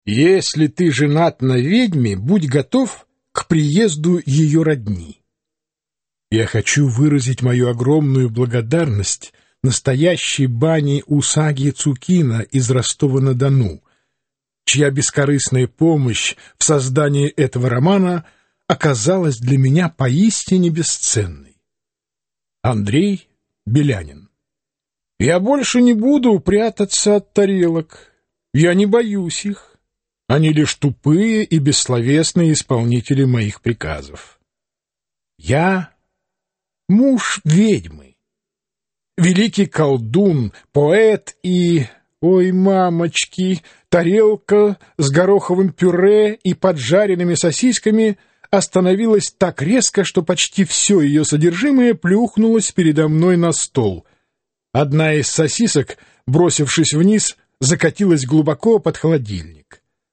Аудиокнига Сестренка из преисподней | Библиотека аудиокниг